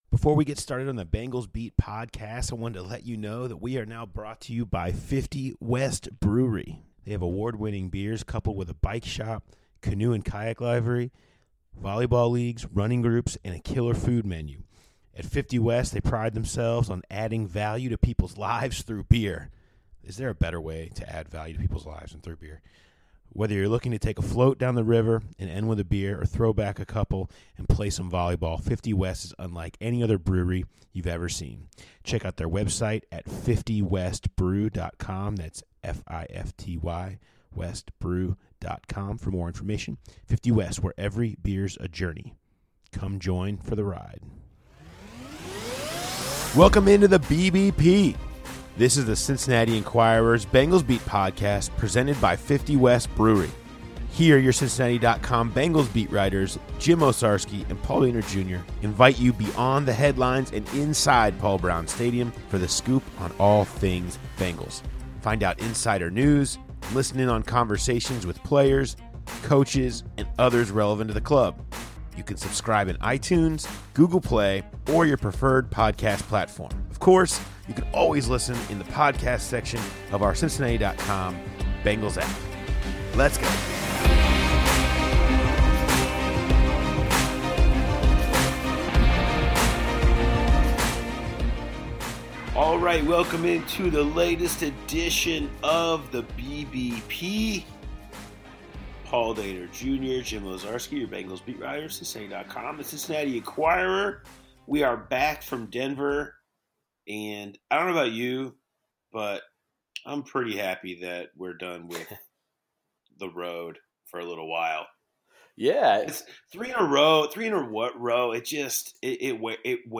Hear from Burfict and offensive coordinator Bill Lazor.